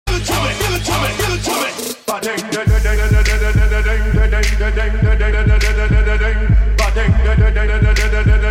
• Качество: 128, Stereo
Trap
Крутой трэп и тверк